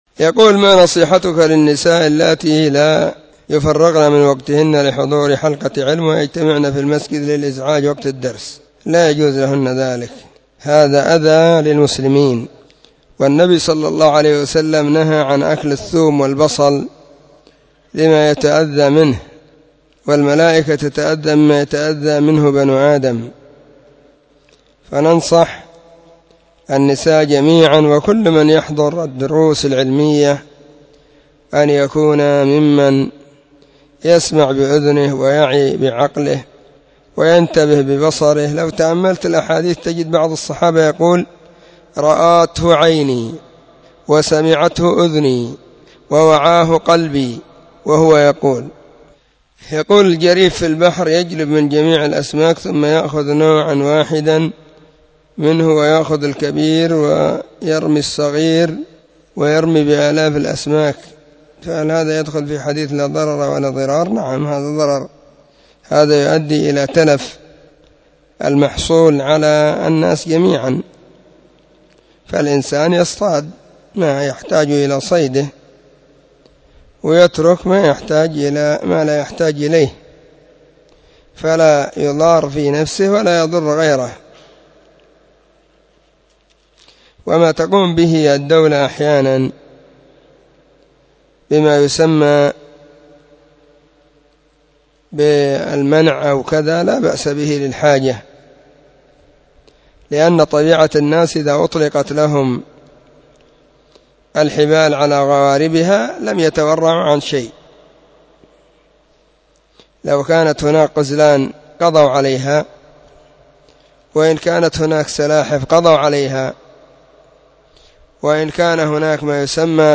📢مسجد – الصحابة – بالغيضة – المهرة، اليمن حرسها الله.